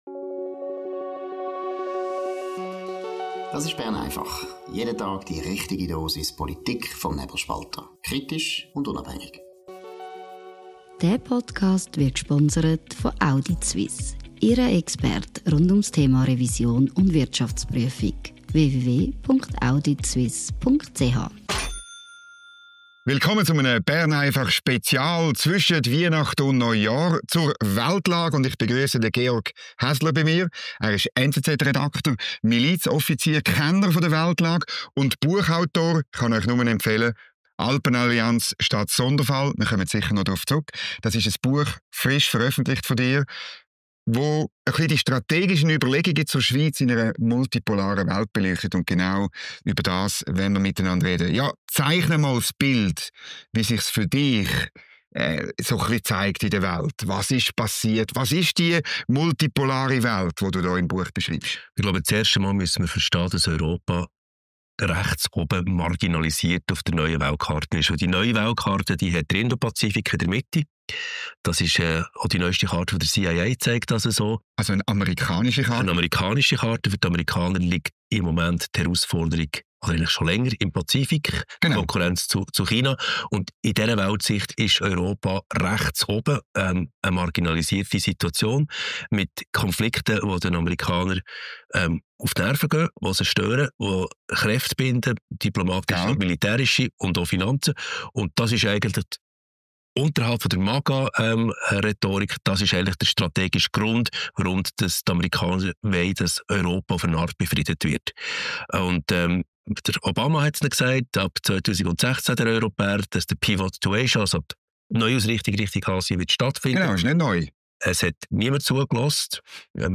Bern einfach Spezial zur Weltlage: Die Redaktion des Nebelspalters spricht mit ausgewählten Gästen aus Politik, Journalismus und Wissenschaft über die entscheidende Frage der internationalen Politik.